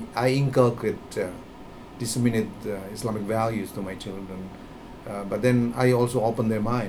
S1 = Taiwanese female S2 = Indonesian male Context: S1 has asked S2 about his Muslim beliefs, and he is talking about imparting religious knowledge to his children.
While it is true that S2 pronounces inculcate as [ɪŋkəlkreɪt] , with an unexpected [r] in the final syllable, and also that he pronounces diseminate as [dɪsɪmɪneɪt] , with [ɪ] rather than the expected [e] in the second syllable, it is not clear if these features contribute to the lack of understanding.